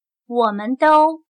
我们都/wǒmen dōu/Todos somos